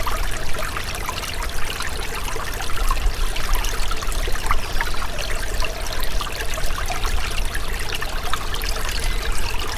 auWater.wav